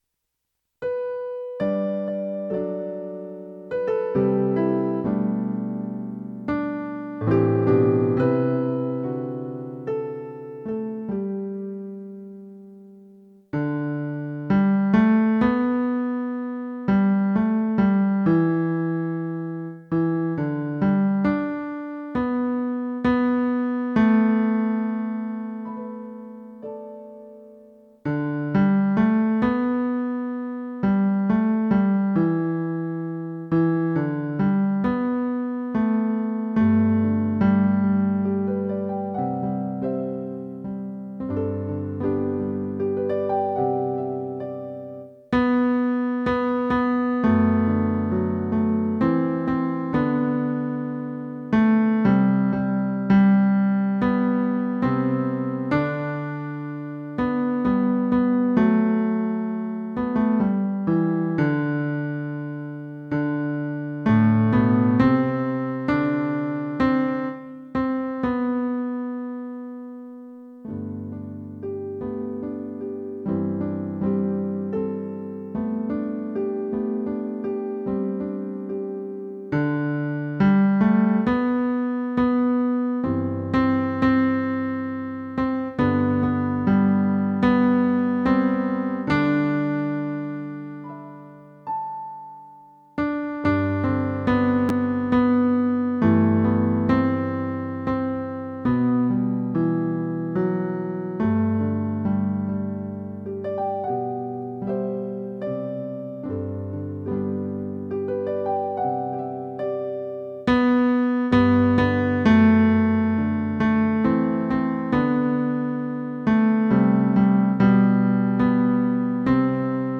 How Can I Keep From Singing? (arr. Andy Beck) - Baritone